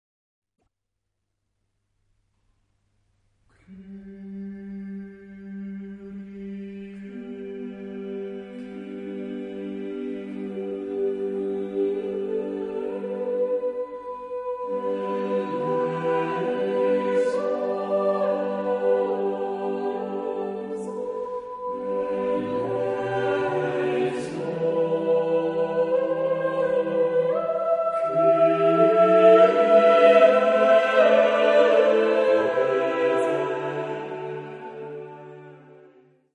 Género/Estilo/Forma: Romántico ; Sagrado ; Misa
Tipo de formación coral: SATB  (4 voces Coro mixto )
Tonalidad : sol mayor